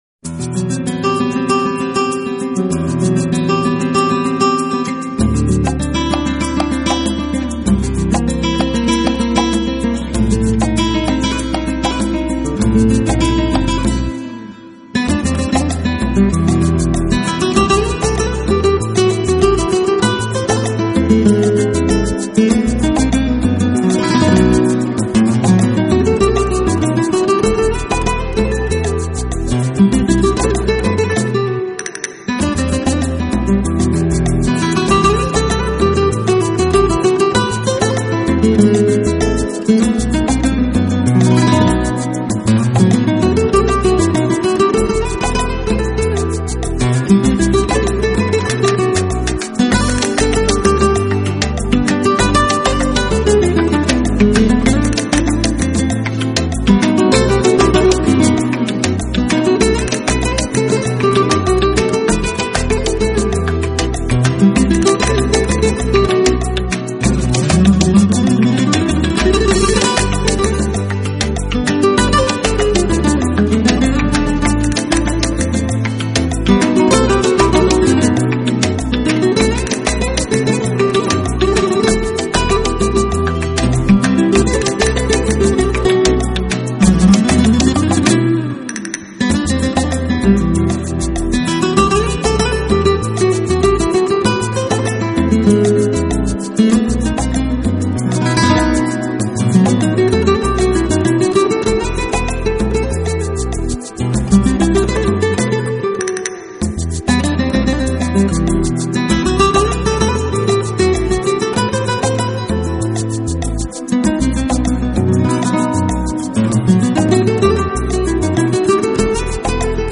更添感染力，抢耳的吉它音色与敲击配合，令人动容！